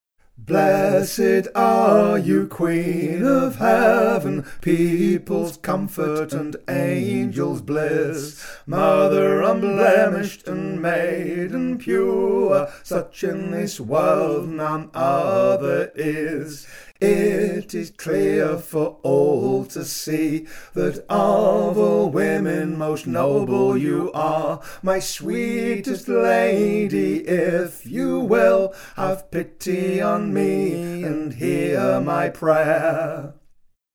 composed in historically informed style
recorder and vielle
harp, gittern and oud